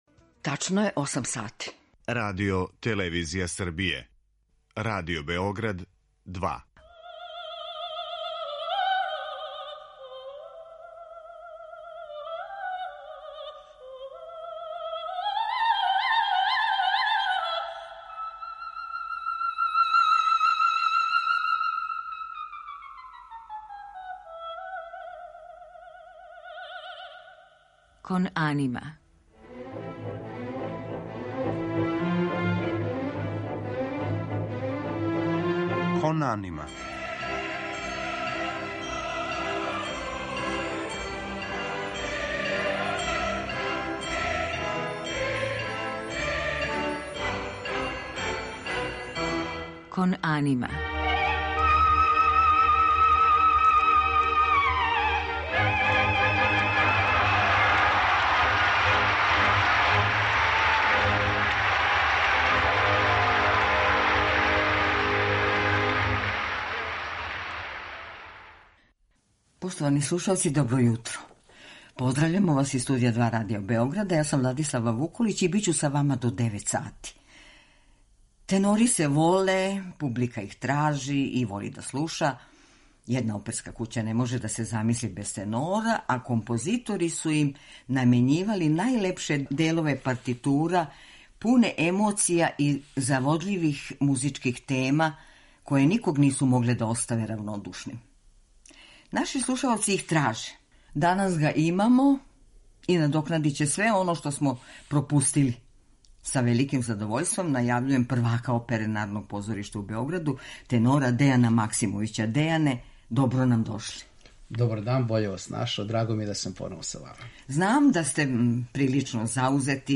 тенор
арије Вердија и Пучинија